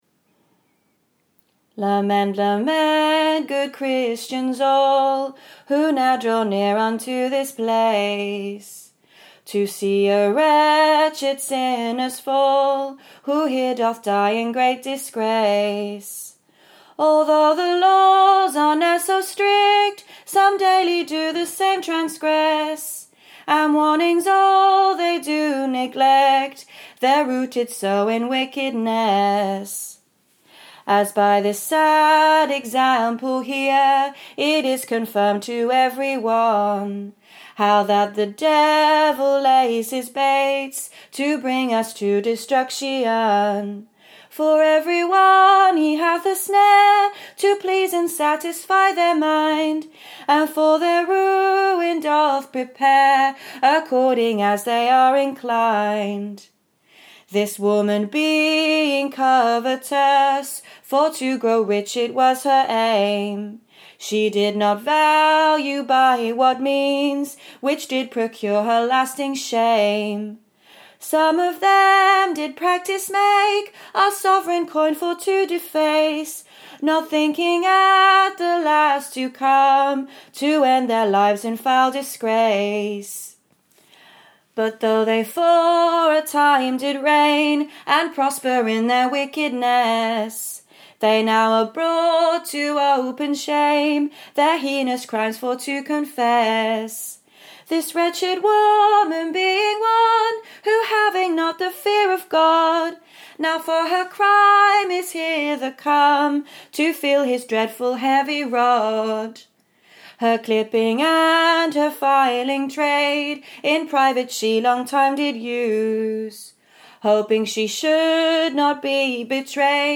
Execution Ballads